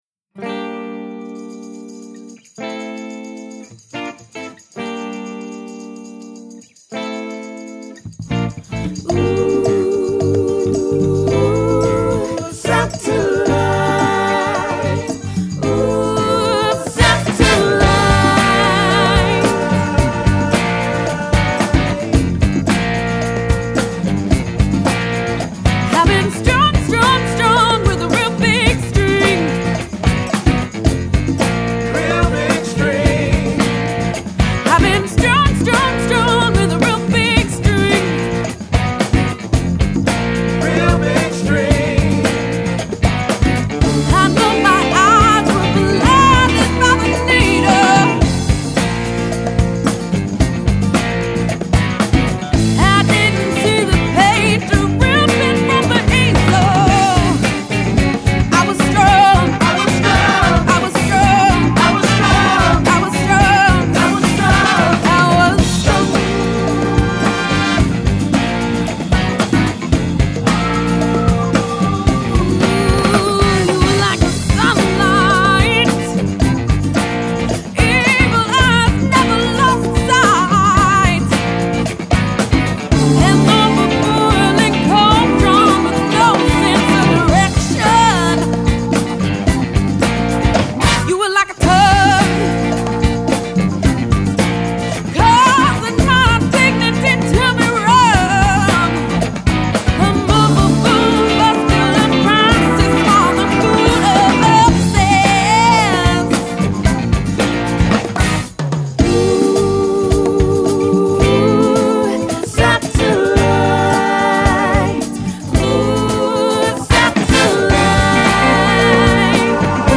the power and sweet sound of her voice